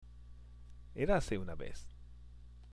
＜発音と日本語＞
（エラセ　ウナ　ベス）